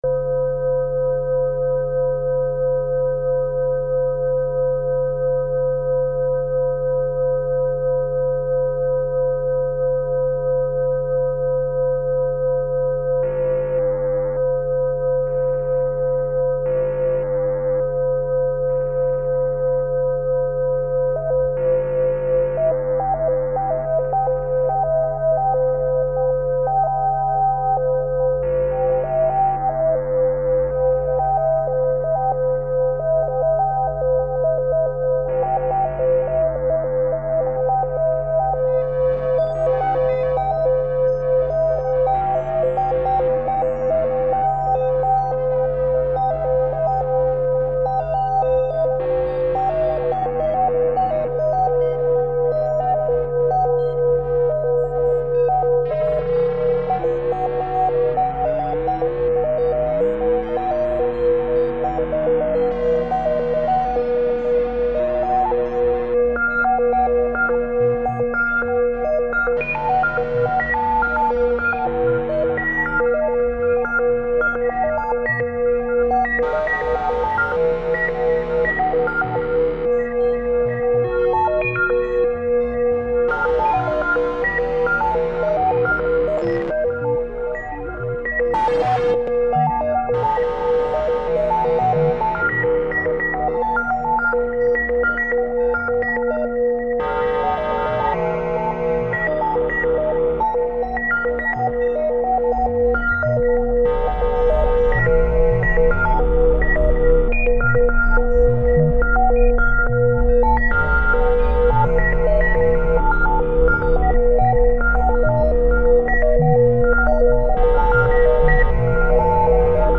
a bit more of dvco + bd plastic and distortion symmetry (last 30 seconds gets loud)
DN drone-ish stuff, just lfos and ratio offsets, I miss the DN…